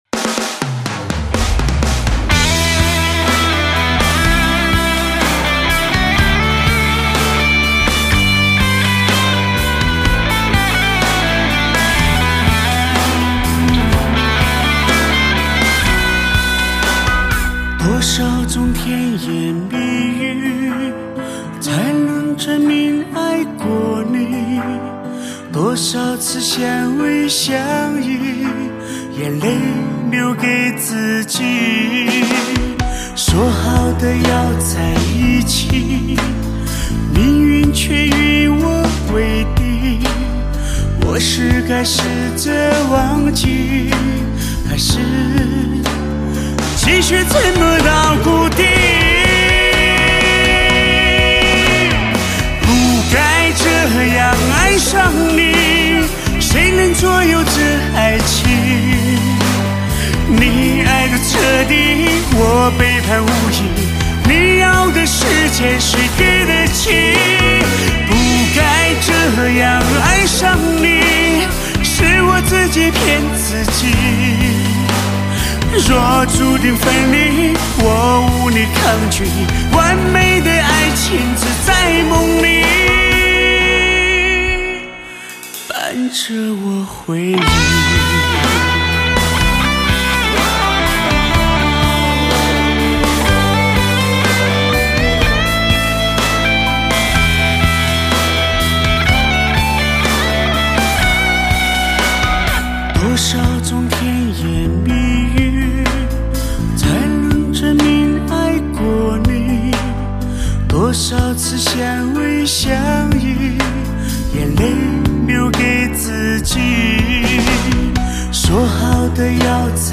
缔造伤感情歌。此歌特意为曾经真爱过的人全力谱写，旋律极尽优美，演唱更是令人回味无穷。